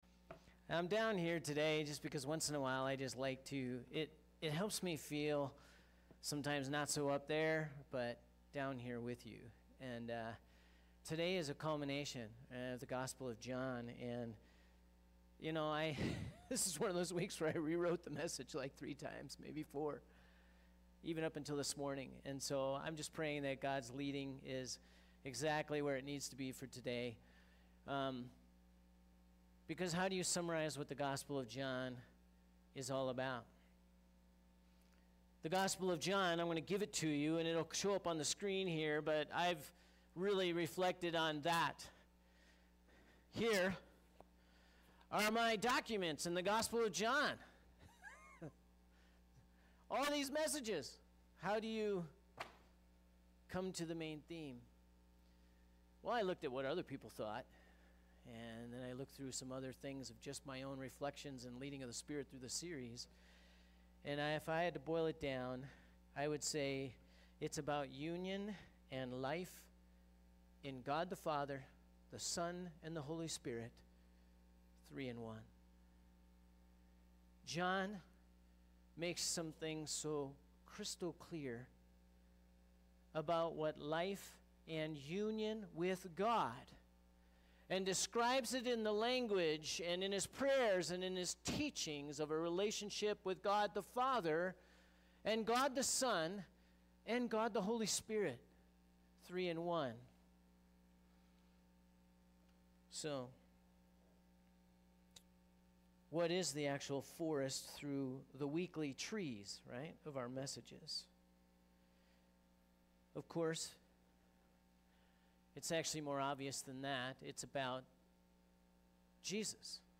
As we engage a wrap up message from our Gospel of John series, this Sunday, what should be emphasized?